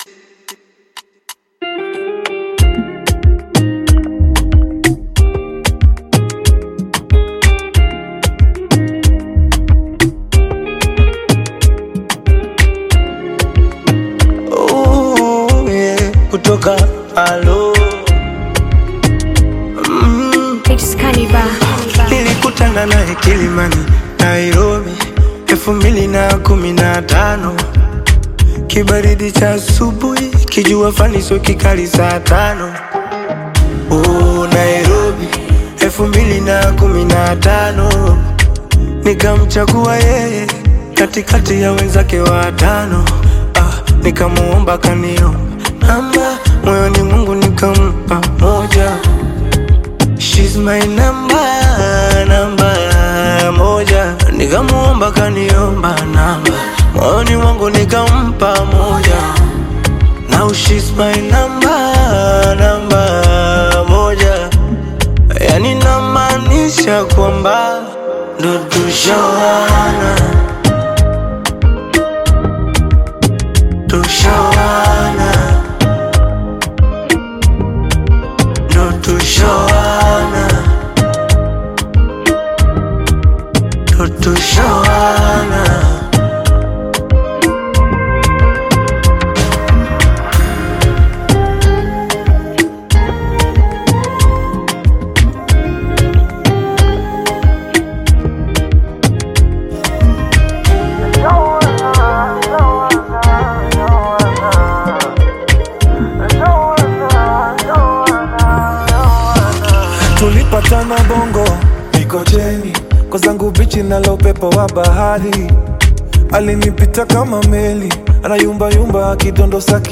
Bongo Flava
This catchy new song